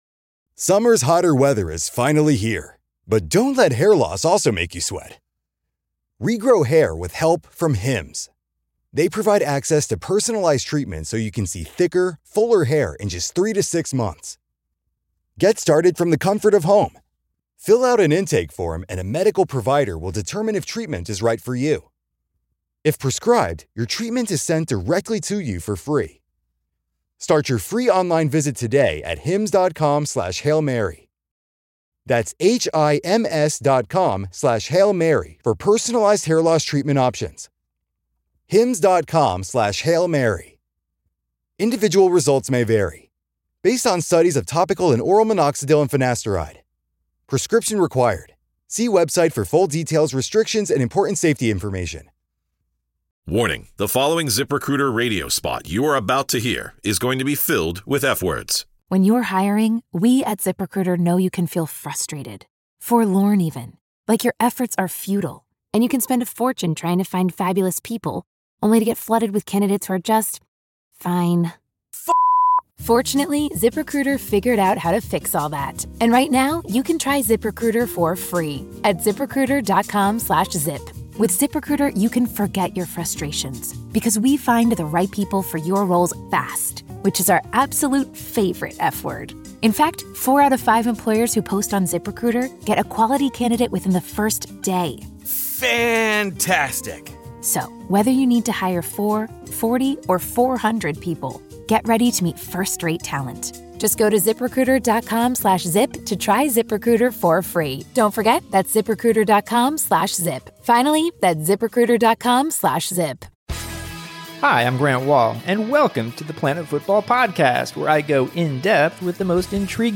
They have a wide-ranging discussion of the World Cup on and off the field.